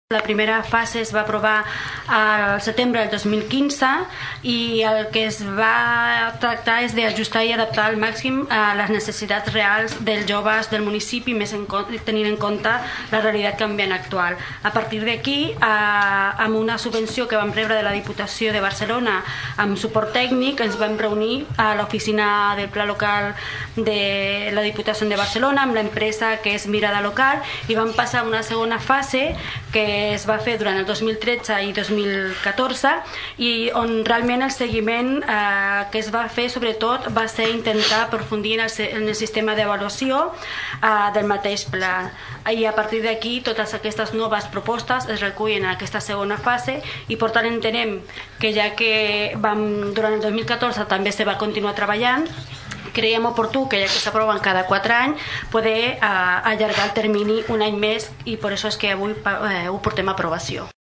La regidora de Joventut, Eliana Romera, recordava les dues fases del pla.